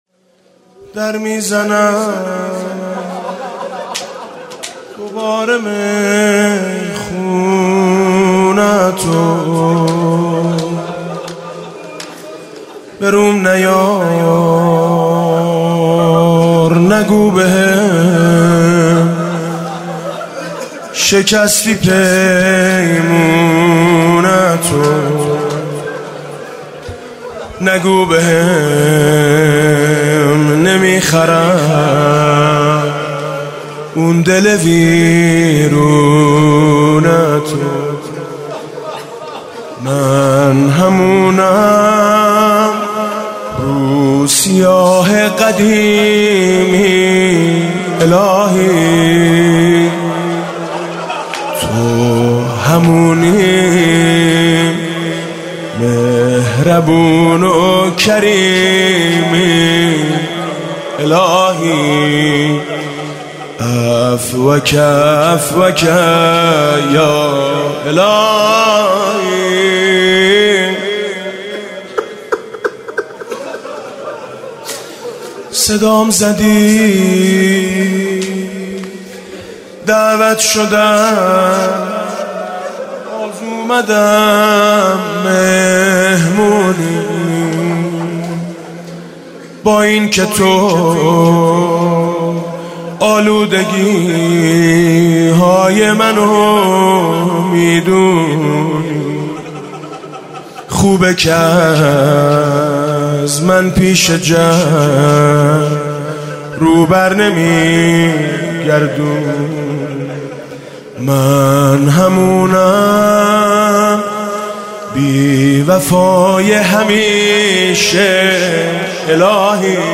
music-icon مناجات: من همونم، تو همونی حاج میثم مطیعی